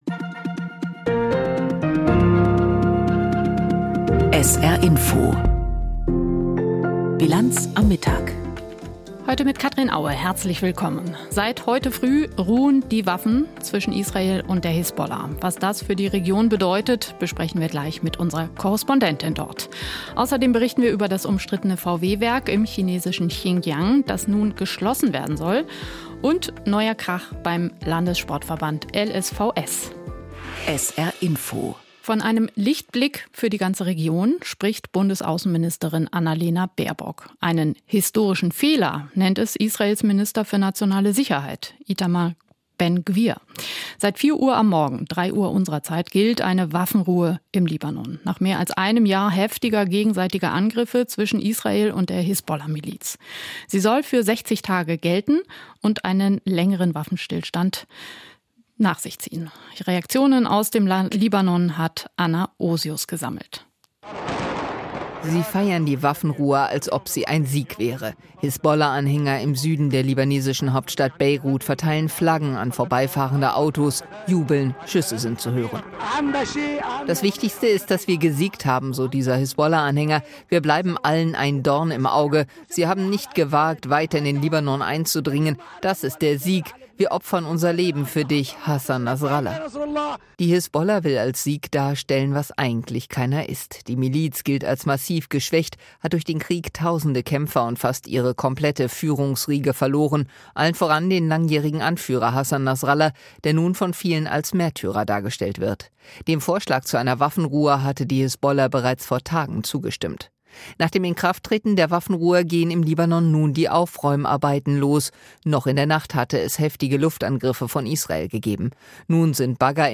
Aktuelles und Hintergrnde zu Entwicklungen und Themen des Tages aus Politik, Wirtschaft, Kultur und Gesellschaft in Berichten und Kommentaren.